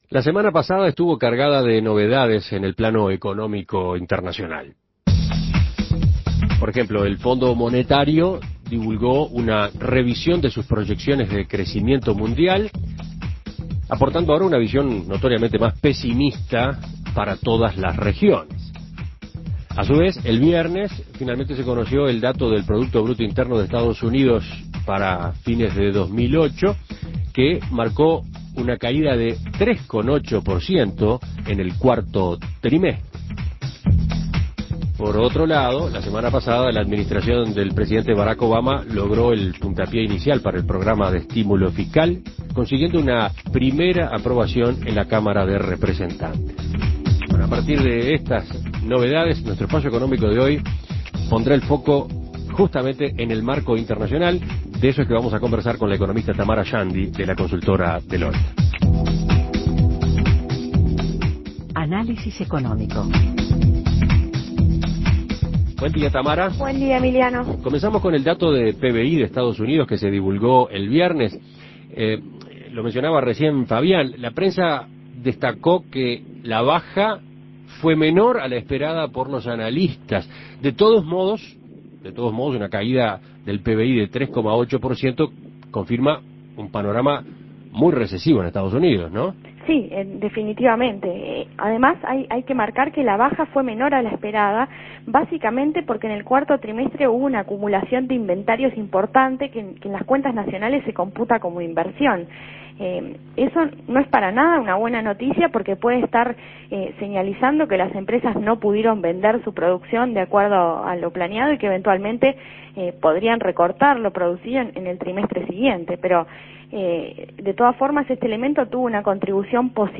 Análisis Económico La recesión en Estados Unidos y la revisión de las proyecciones de crecimiento mundial.